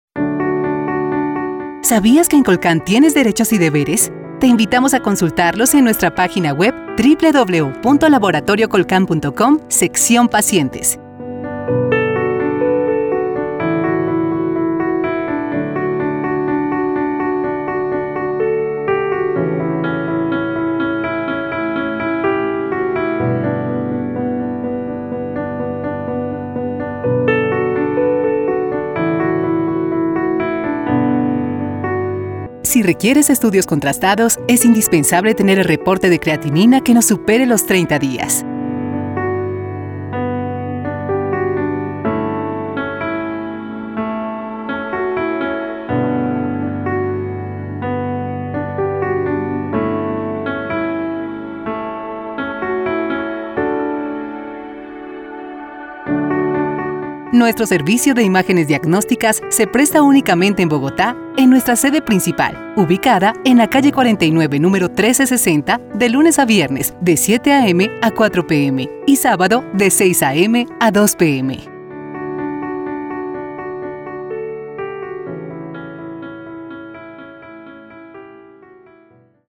Sprechprobe: Industrie (Muttersprache):
IVR COLCÁN.mp3